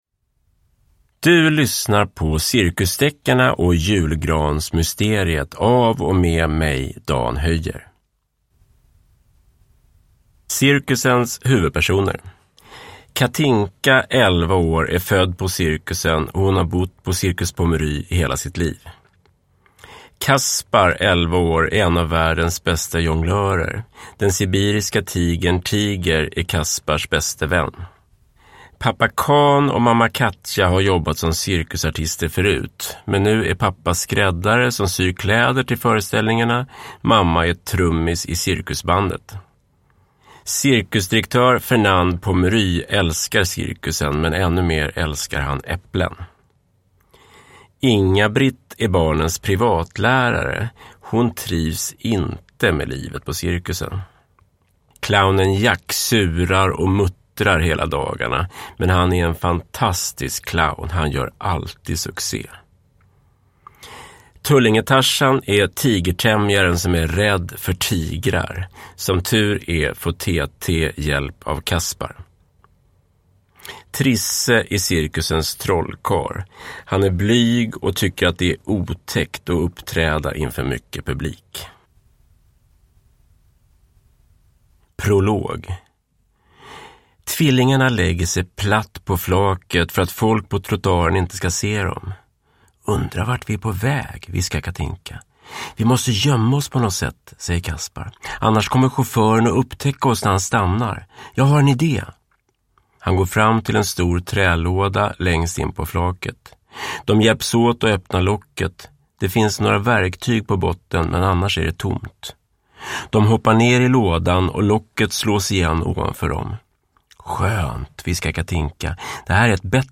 Cirkusdeckarna och julgransmysteriet – Ljudbok